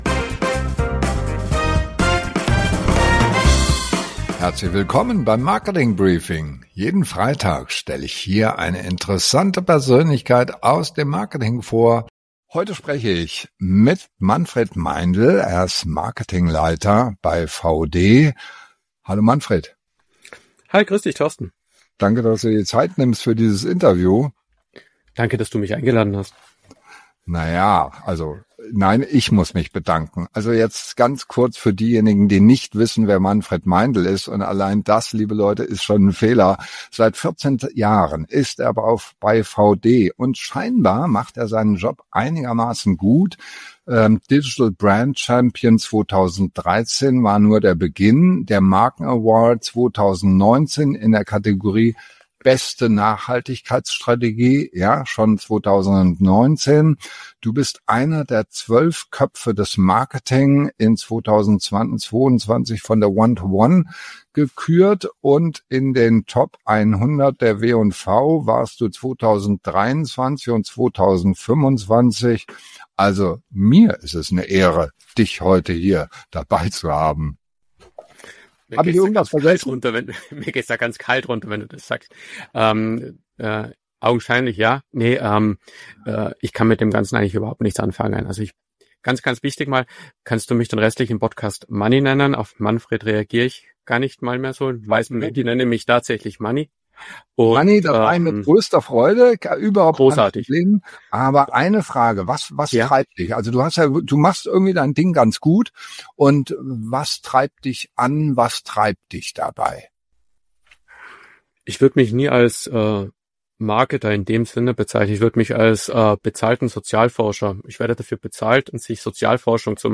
Jeden Freitag Interviews mit spannenden Persönlichkeiten aus der Digital- & Marketing-Szene